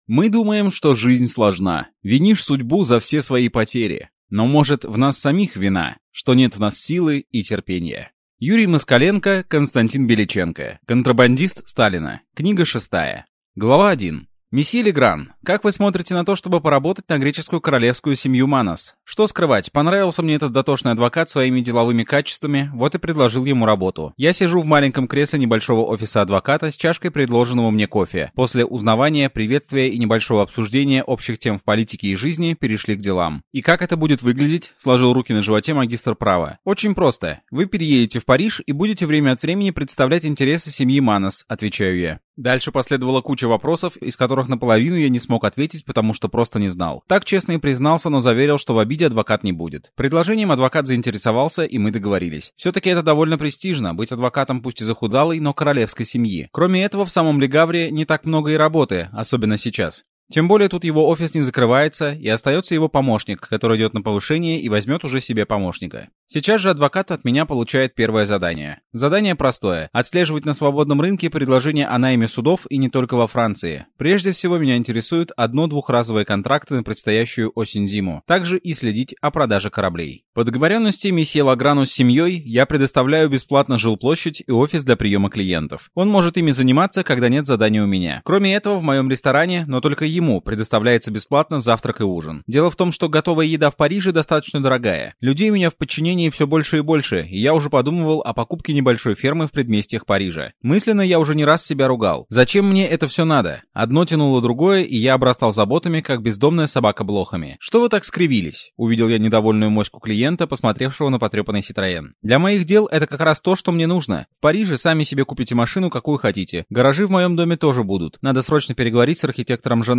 Аудиокнига Контрабандист Сталина Книга 6 | Библиотека аудиокниг